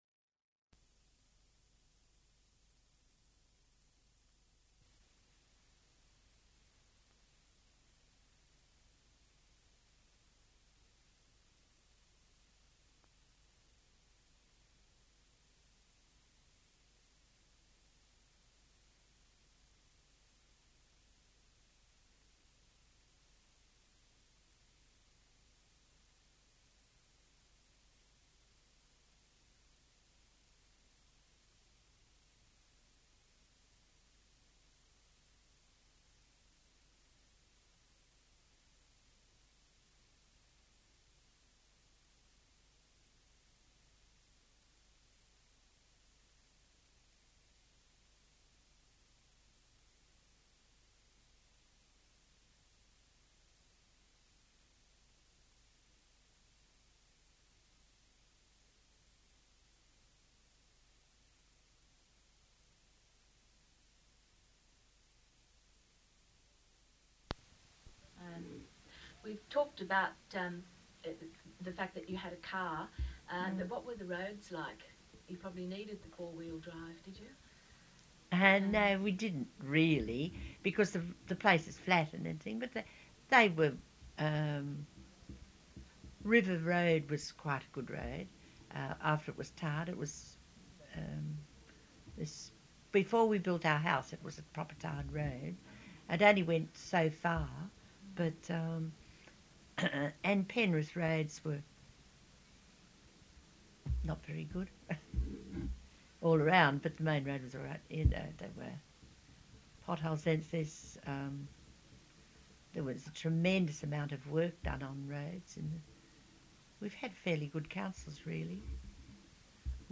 Interview Audio Recordings